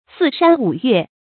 四山五岳 注音： ㄙㄧˋ ㄕㄢ ㄨˇ ㄩㄝˋ 讀音讀法： 意思解釋： 泛指四面八方各個地區。